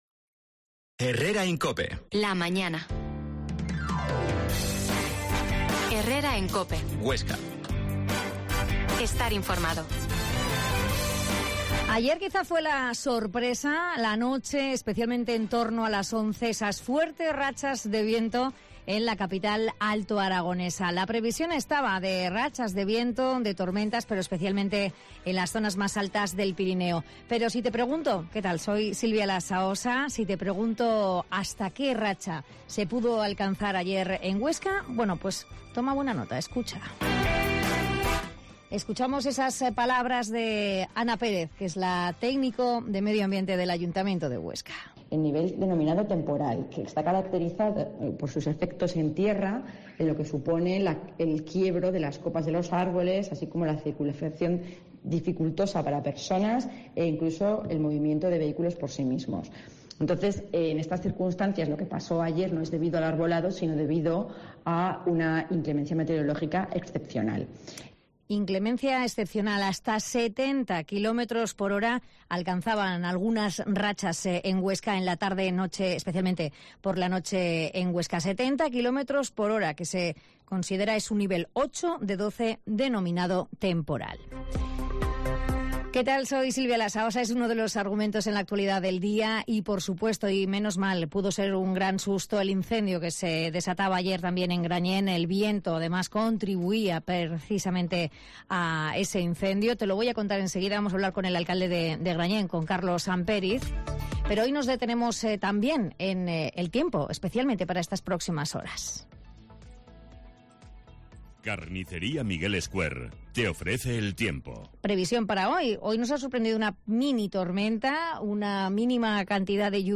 Herrera en COPE Huesca 12.50h Entrevista al alcalde de Grañén, Carlos Samperiz